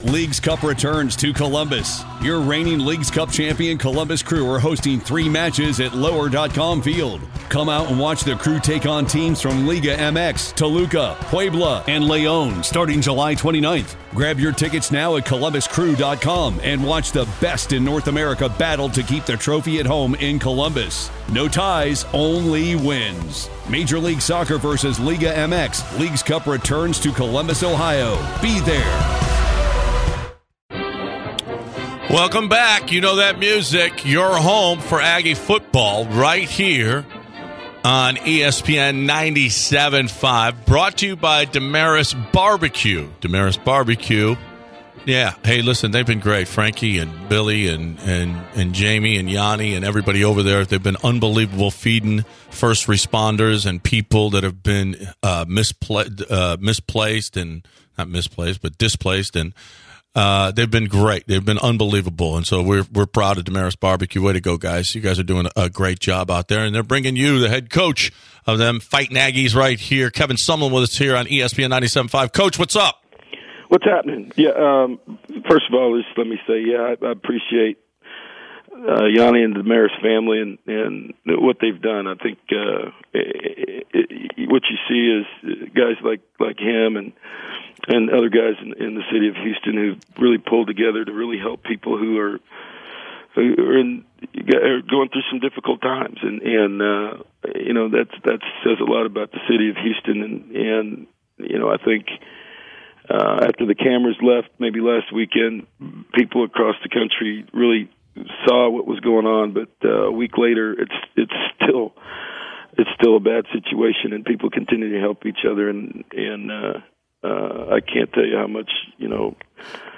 Texas A&M football head coach Kevin Sumlin speaks